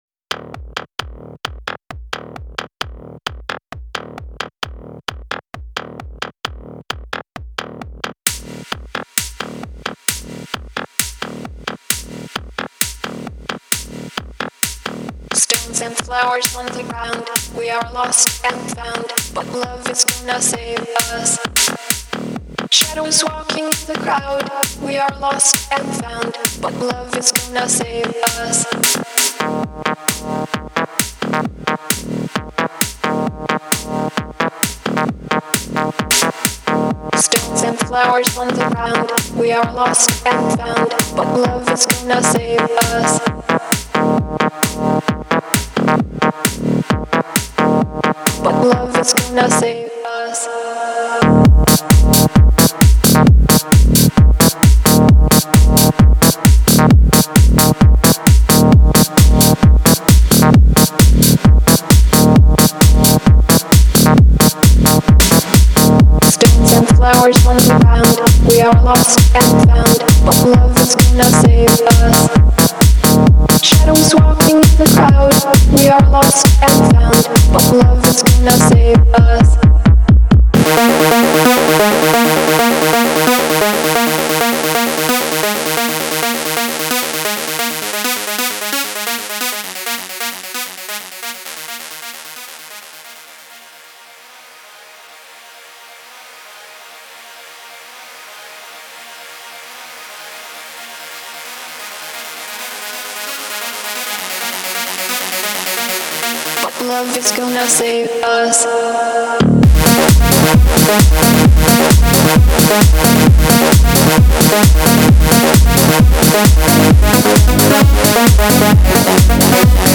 Музыка для тренировок